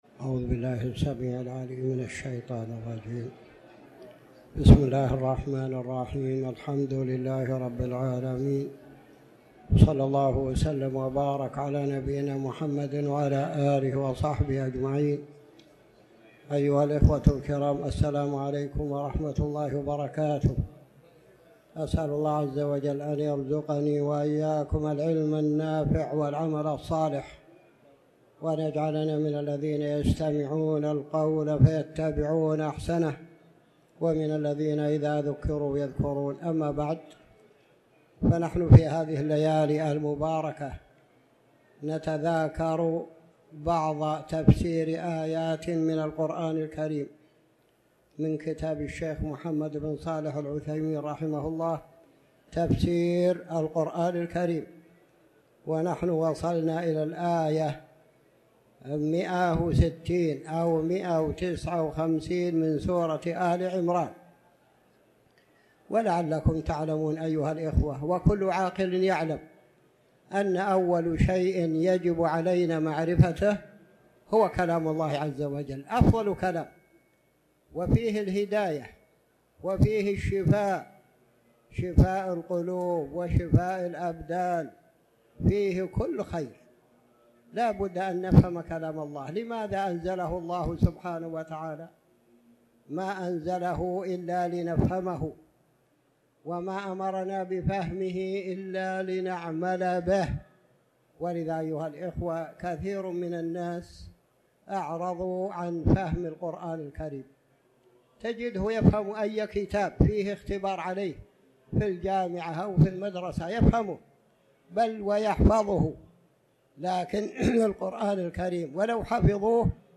تاريخ النشر ٣ ربيع الأول ١٤٤٠ هـ المكان: المسجد الحرام الشيخ